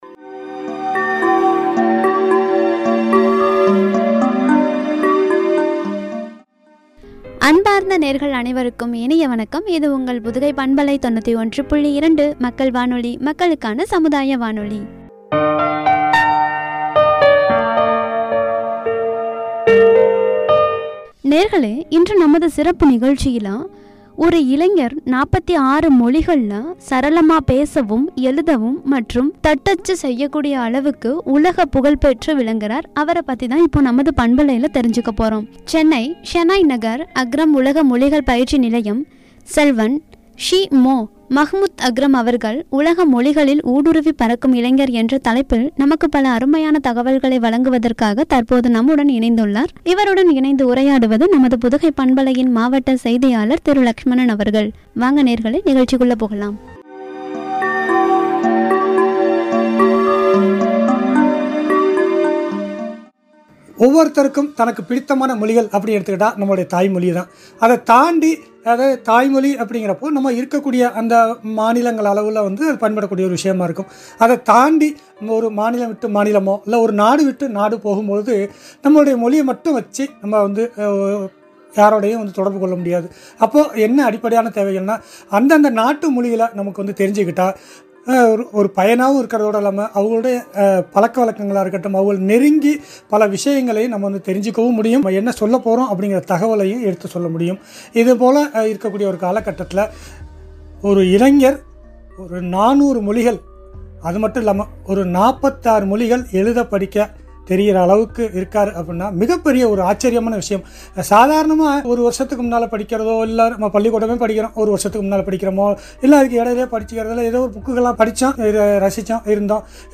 என்ற தலைப்பில் வழங்கிய உரையாடல்.